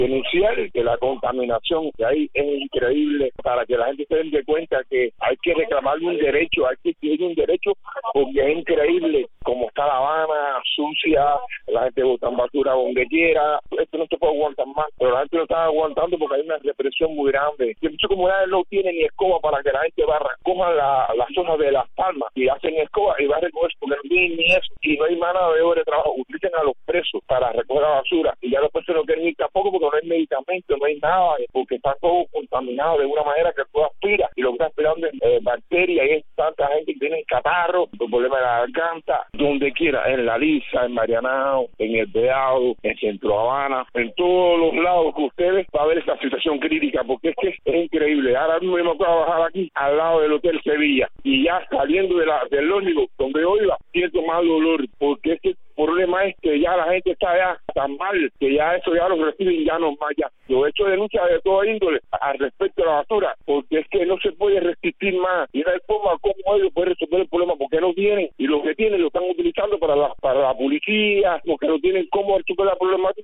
Declaraciones del opositor cubano